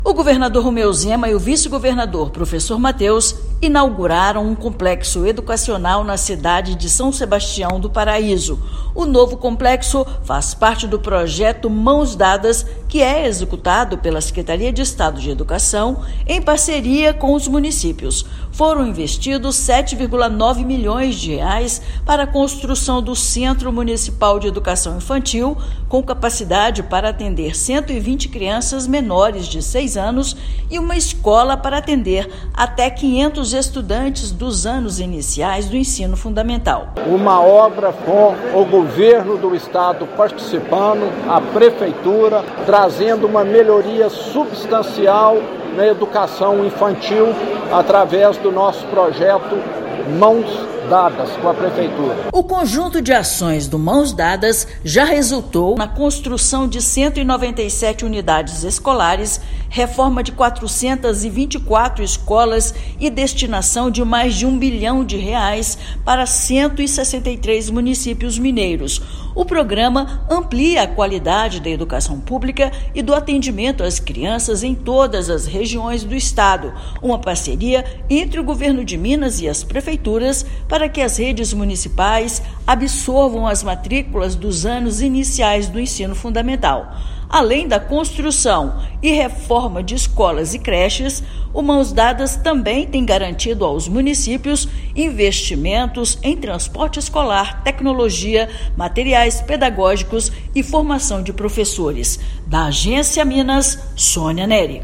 [RÁDIO] Entrega de complexo educacional reforça parceria do Governo de Minas e municípios pelo avanço do ensino